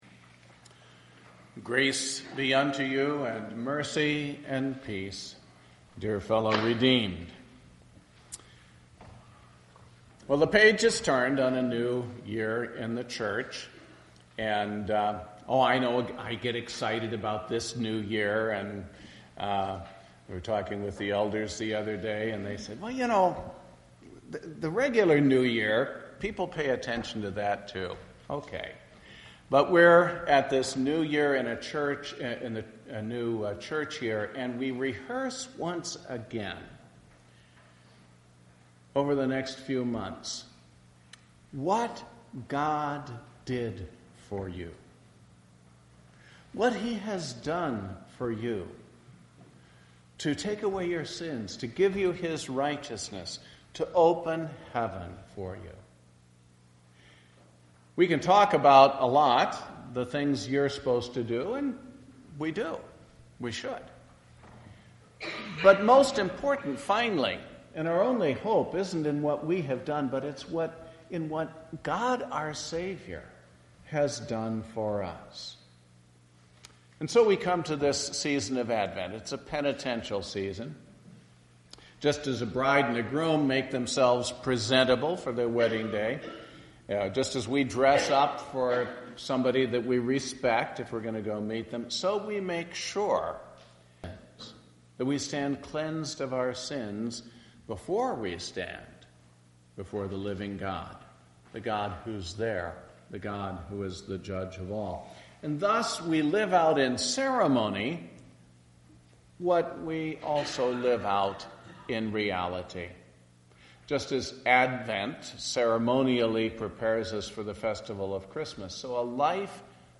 Sermon based on Mark 13:24-37. First Sunday in Advent.